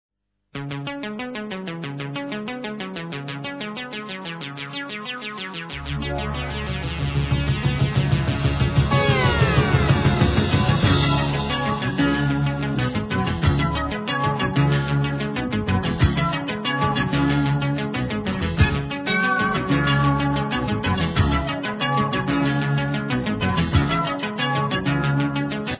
Recorded at Nemo Studios, London, England, 1977